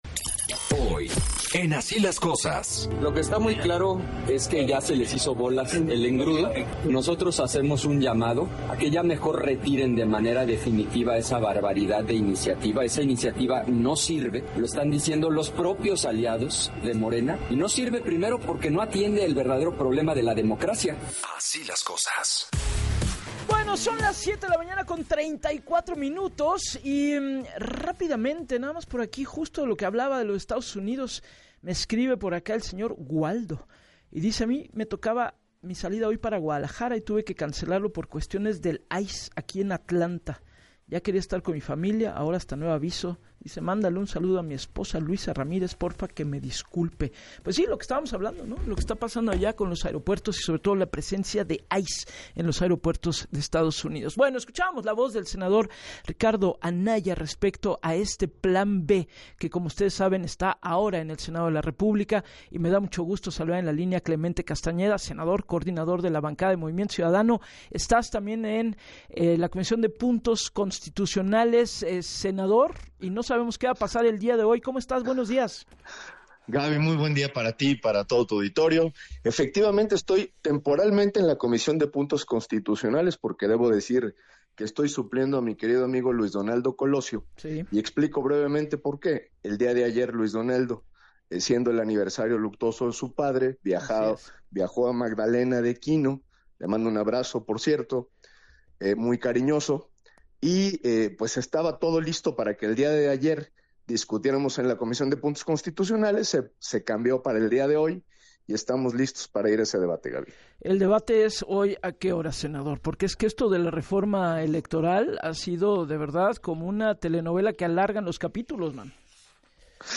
En entrevista para “Así las Cosas” con Gabriela Warkentin, el legislador de MC detalló que la cita continúa para este martes a las 13:30 para iniciar los trabajos en Comisiones, recordó que el documento ya se encuentra en manos de los legisladores tras la corrección de los errores que tenía el proyecto de dictamen, ahora, dijo viene lo cansado, que es el análisis de la parte sustantiva, lo que tiene que ver con la revocación de mandato, que dijo, “el oficialismo quiere para legitimar el poder”.